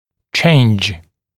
[ʧeɪnʤ][чейндж]изменение; изменять